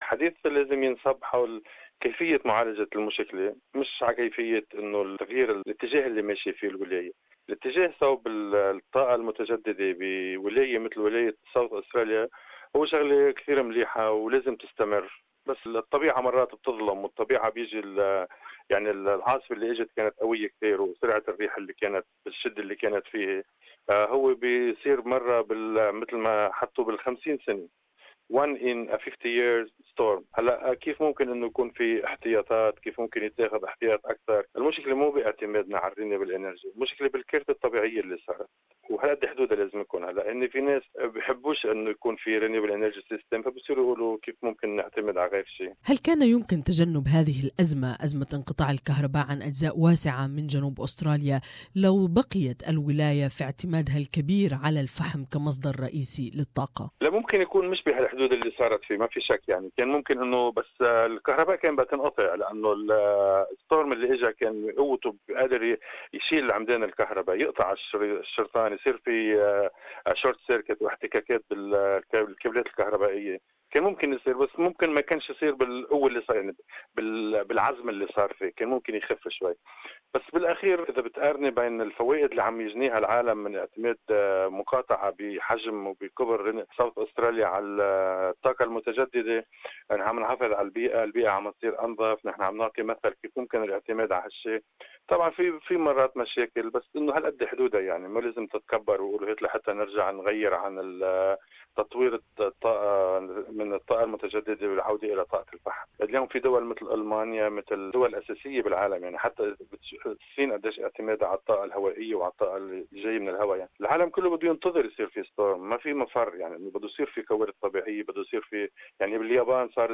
Prime Minister Malcolm Turnbull says Labor governments have imposed ideological renewable energy targets that are aggressive and unrealistic. Meanwhile, Opposition leader Bill Shorten says renewable energy targets had nothing to do with yesterday's statewide blackout in South Australia. More in this interview